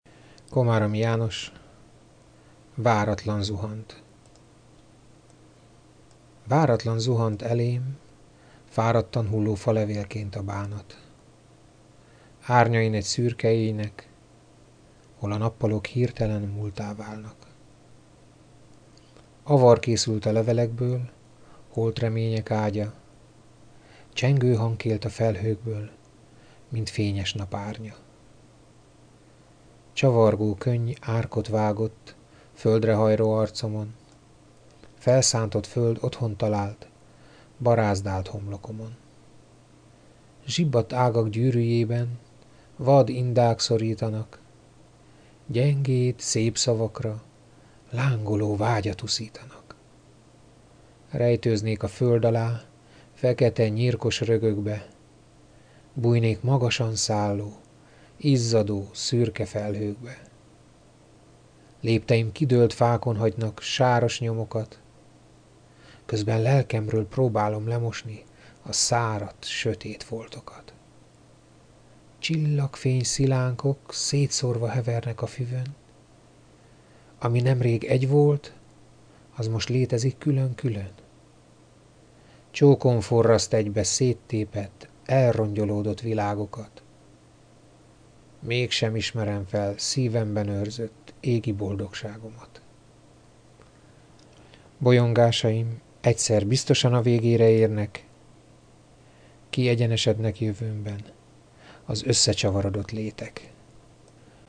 Egyszer azonban gondoltam egyet és elmondtam néhány versemet.